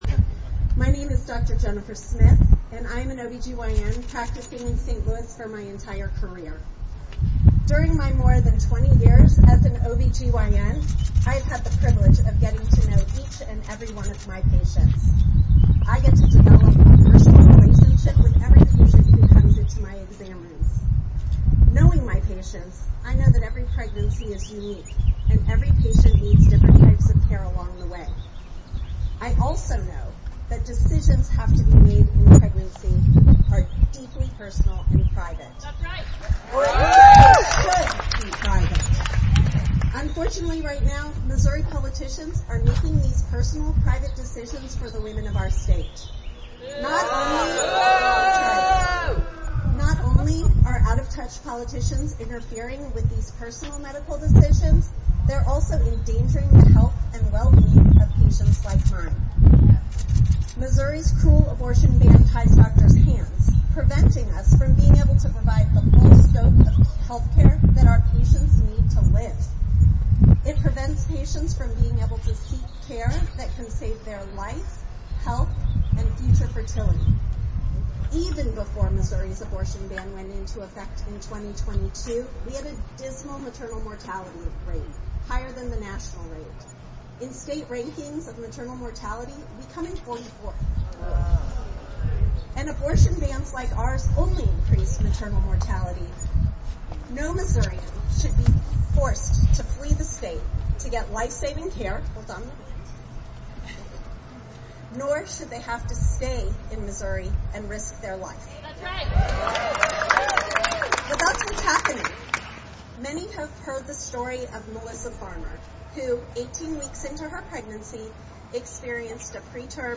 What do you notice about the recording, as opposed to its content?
A rally in support of the initiative for abortion rights started at 11:00 a.m. on the north side of the Capitol.